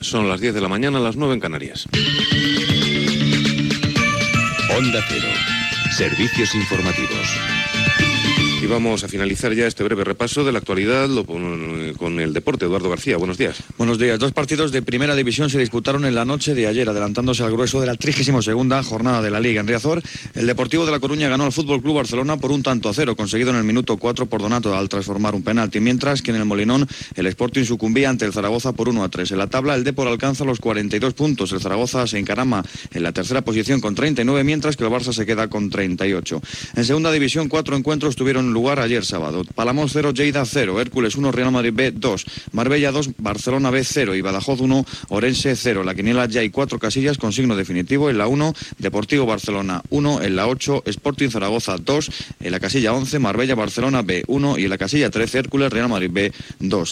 Hora, indicatiu del programa i informació esportiva
Esportiu
FM